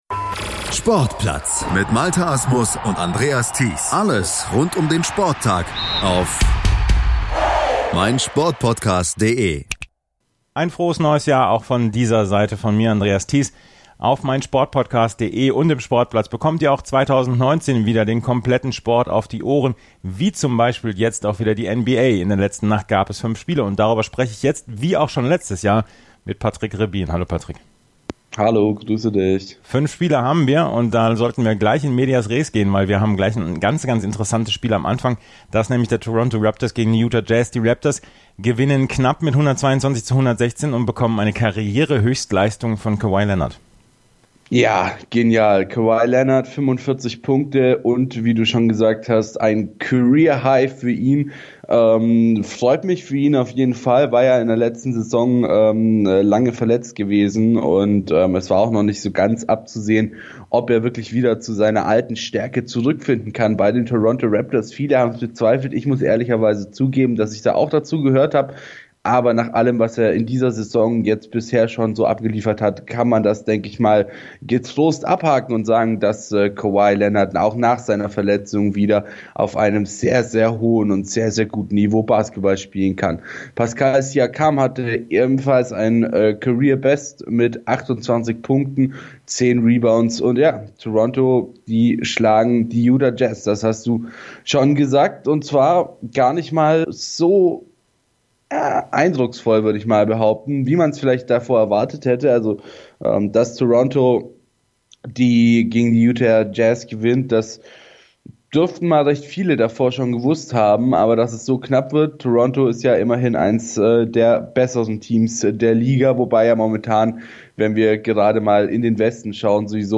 Wie jeden Werktag haben wir einen Experten zu Gast, der über die Spiele der letzten Nacht berichtet.